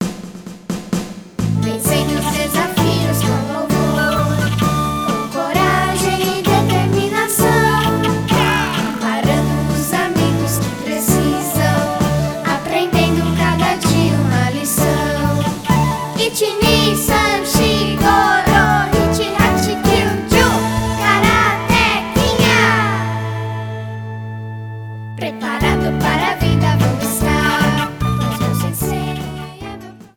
Violão, baixo e bateria
Flauta